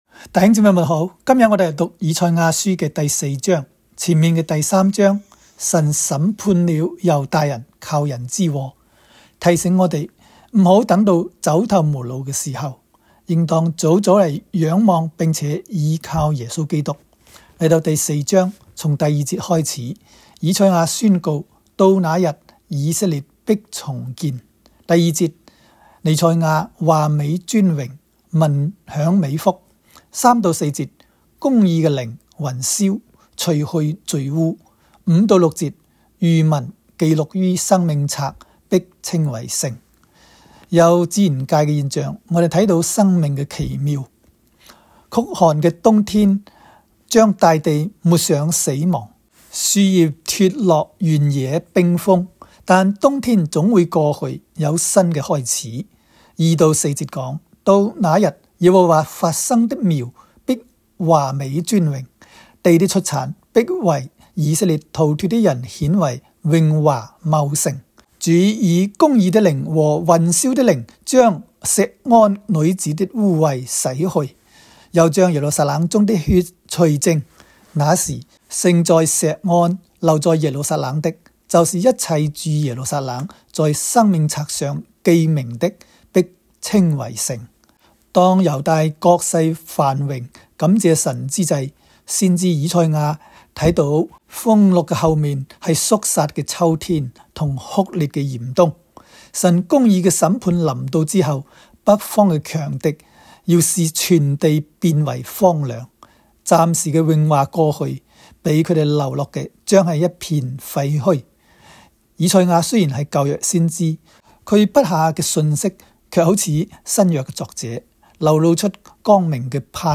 赛04（讲解-粤）.m4a